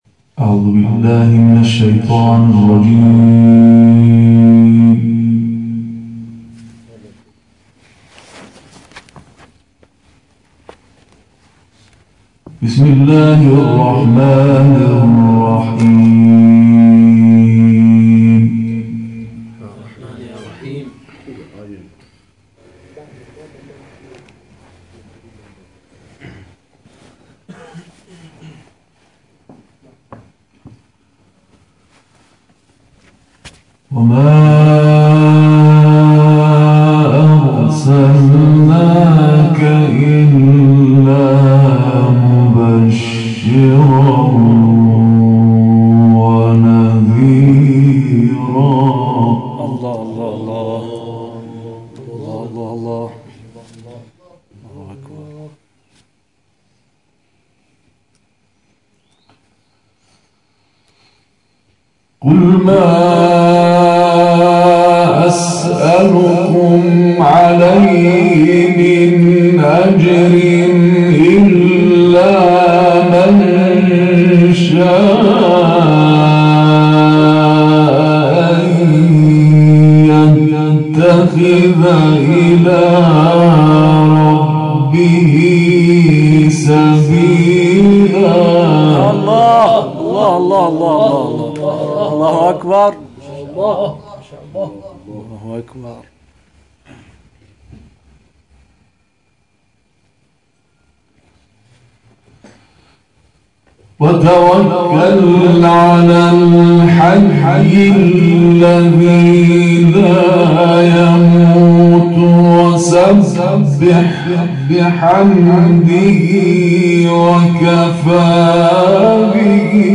محفل انس با قرآن کریم در مسجد حضرت ابوالفضل(ع) واقع در شهر زاهدان
محفل انس با قرآن کریم شنبه 5 اسفند ماه پس از نماز مغرب و عشاء در مسجد حضرت ابوالفضل(ع) واقع در شهر زاهدان